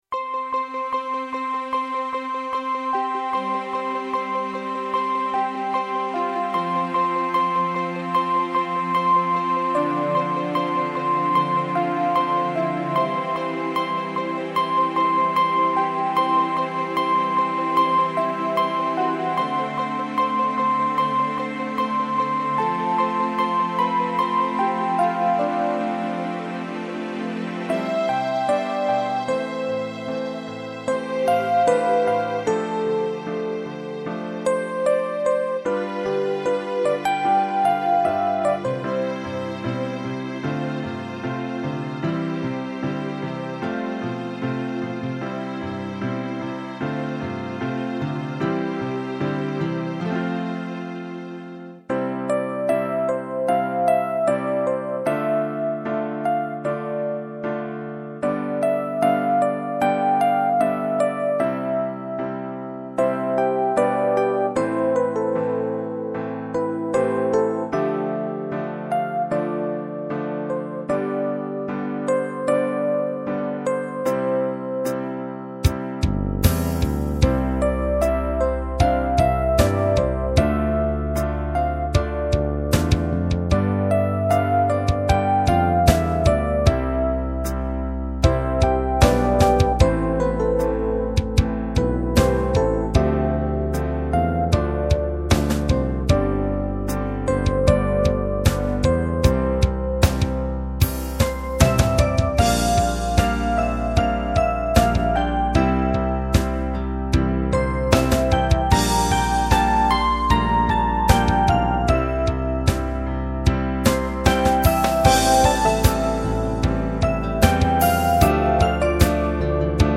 ・エンディングテーマっぽい8分の曲